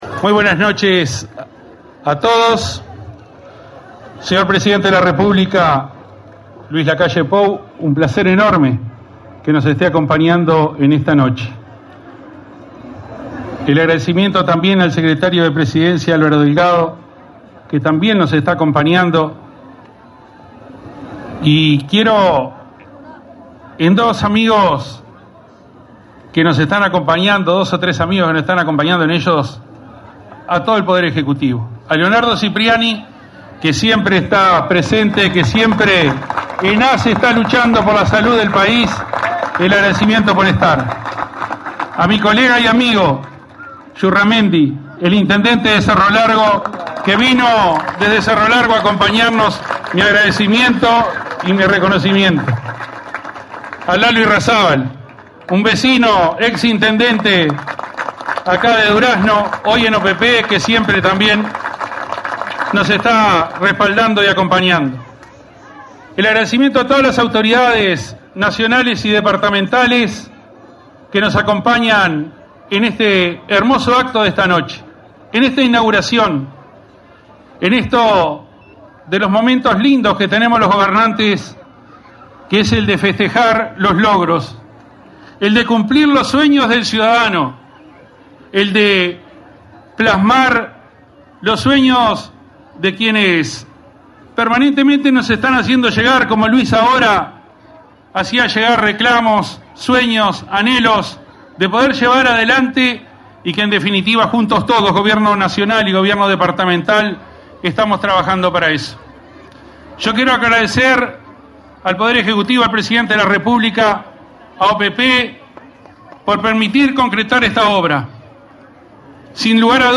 Palabras del intendente de Tacuarembó, Wilson Ezquerra
Palabras del intendente de Tacuarembó, Wilson Ezquerra 13/09/2022 Compartir Facebook X Copiar enlace WhatsApp LinkedIn Con la presencia del presidente de la República, Luis Lacalle Pou, este 13 de setiembre se realizó la inauguración de la terminal de ómnibus en Paso de los Toros. En el acto participó el intendente de Tacuarembó, Wilson Ezquerra.